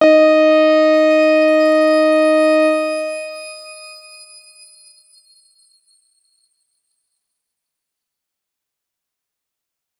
X_Grain-D#4-mf.wav